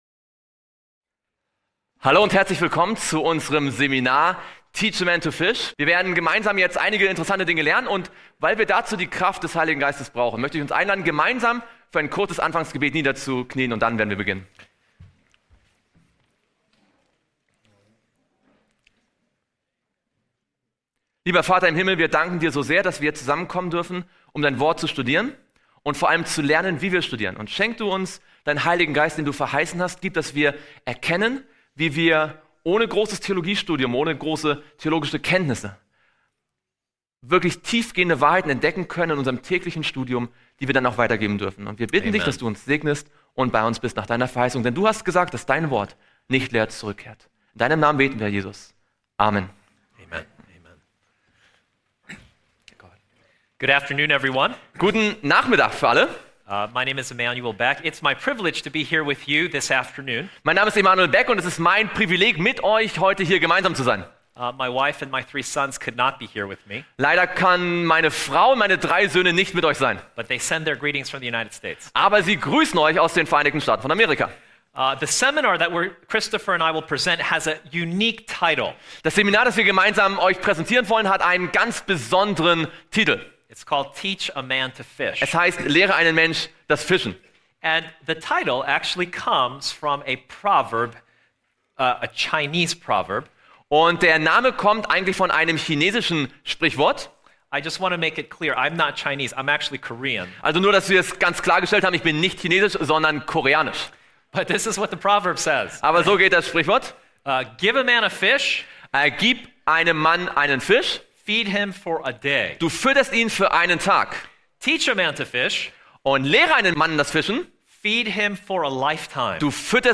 Dieser Workshop wird dir dabei helfen.